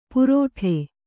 ♪ purōṭhe